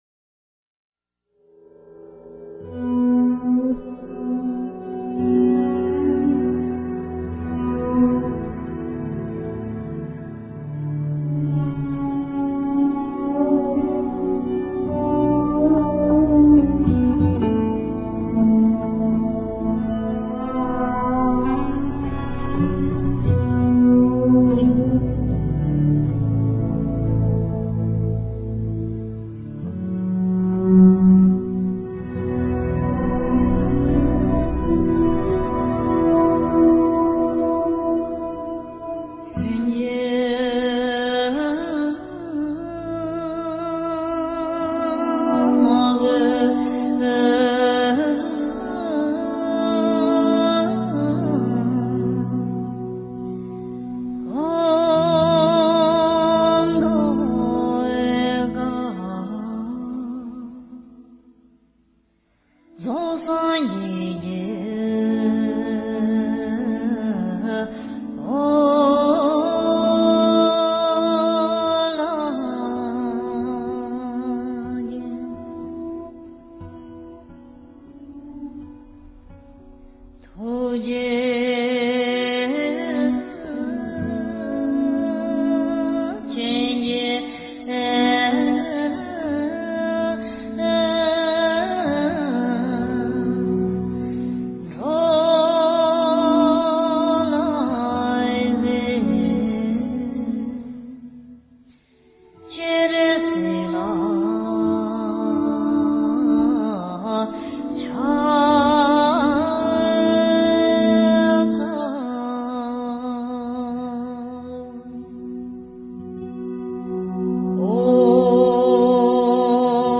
观世音菩萨 Chenrezi--禅定音乐
观世音菩萨 Chenrezi--禅定音乐 冥想 观世音菩萨 Chenrezi--禅定音乐 点我： 标签: 佛音 冥想 佛教音乐 返回列表 上一篇： 智慧 Wisdom--禅定音乐 下一篇： 南无观世音菩萨 Namo Kuan Shi Yin Pusa--禅定音乐 相关文章 纯音乐-一声佛号一声心--水晶佛乐 纯音乐-一声佛号一声心--水晶佛乐...